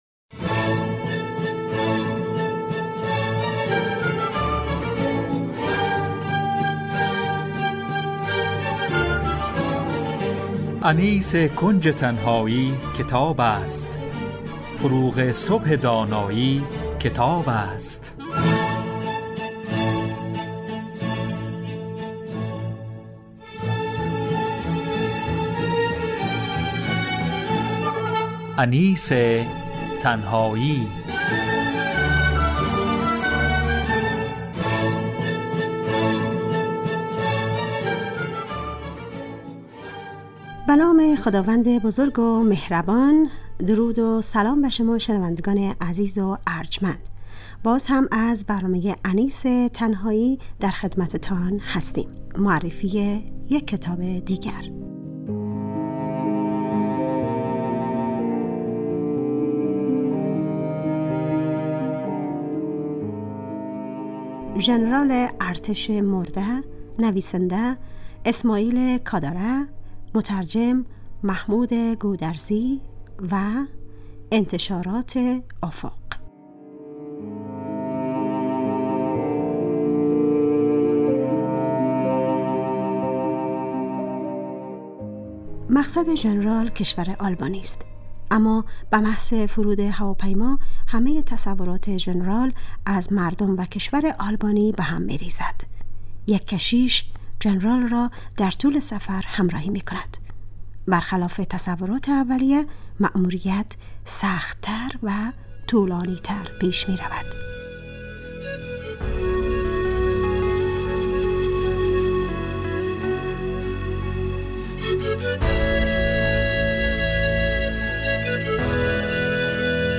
معرفی کتاب